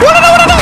war cry